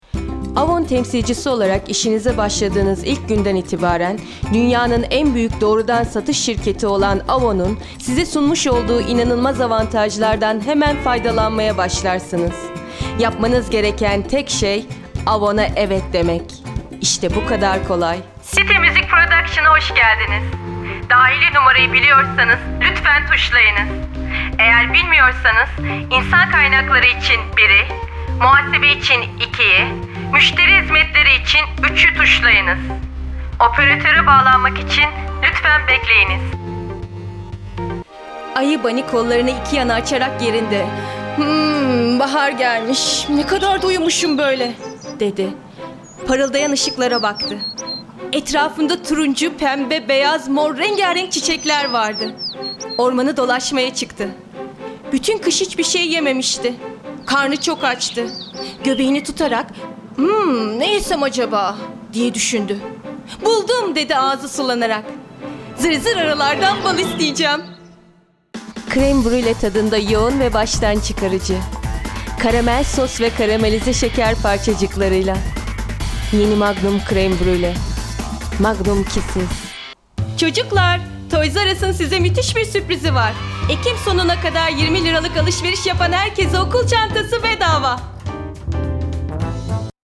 Male 20s , 30s , 40s , 50s , 60s British English (Native) Assured , Authoritative , Bright , Bubbly , Character , Cheeky , Confident , Cool , Corporate , Deep , Engaging , Friendly , Gravitas , Natural , Posh , Reassuring , Sarcastic , Smooth , Soft , Streetwise , Wacky , Warm , Witty , Versatile Animation , Character , Commercial , Corporate , Documentary , Educational , E-Learning , Explainer , Narration , Training , Video Game
Ads_Games_Reel.mp3